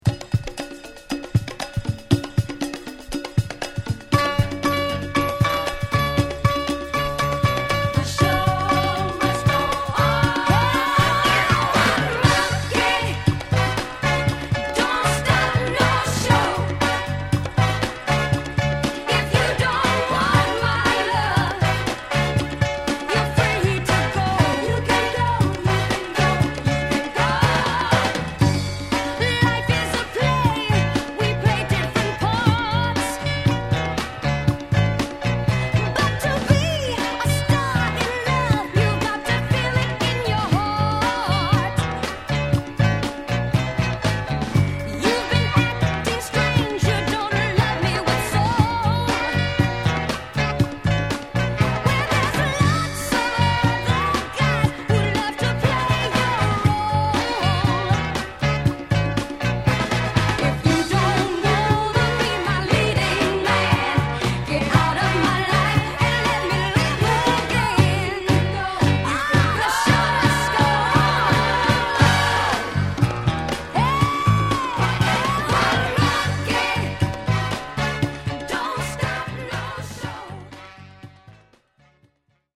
Genre: Northern Soul, Motown Style